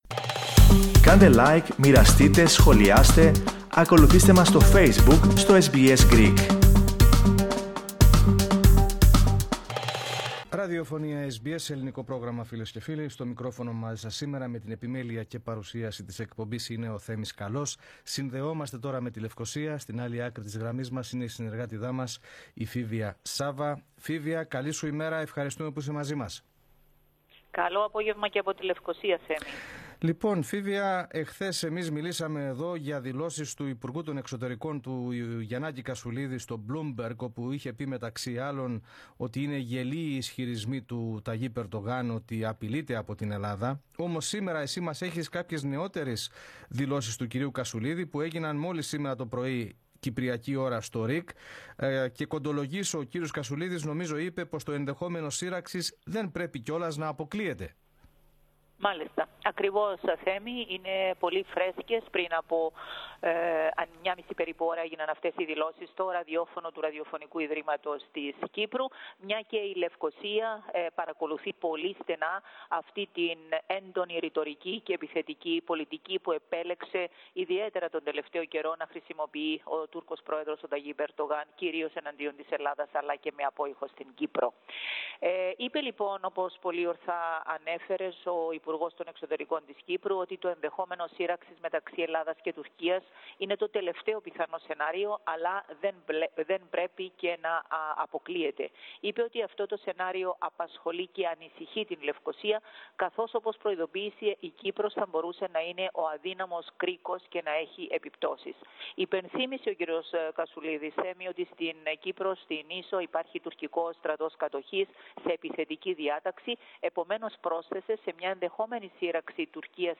Ακούστε ολόκληρη την ανταπόκριση από την Κύπρο, κάνοντας double click στο ηχητικό.